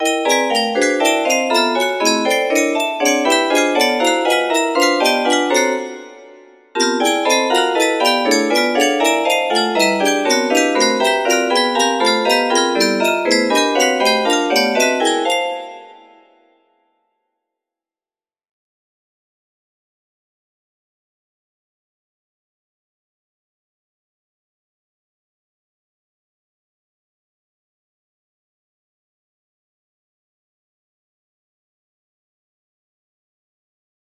P15 music box melody